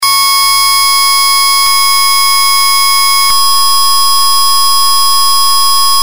На этой странице собраны звуки, которые могут напугать или отпугнуть собак: ультразвуковые сигналы, резкие шумы, свистки и другие эффекты.
Звук, который не перенесет любая собака